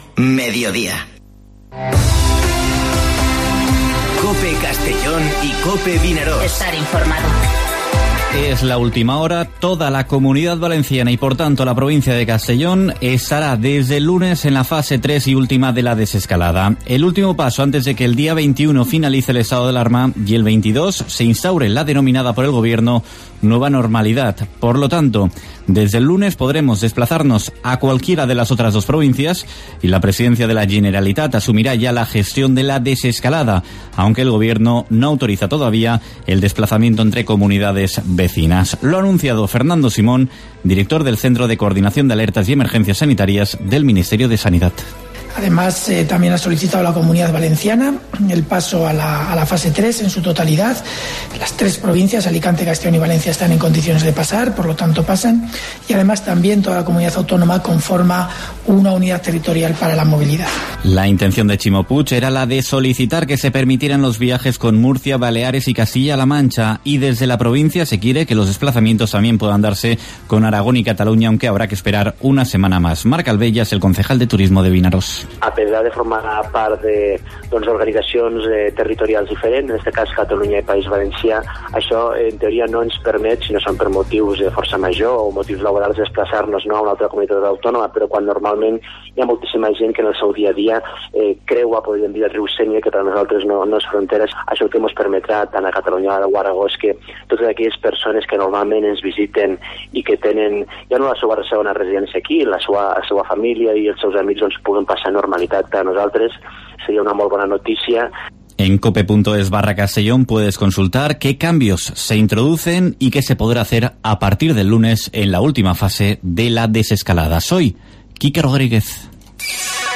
Informativo Mediodía COPE en la provincia de Castellón (12/06/2020)